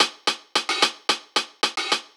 Index of /musicradar/ultimate-hihat-samples/110bpm
UHH_AcoustiHatA_110-05.wav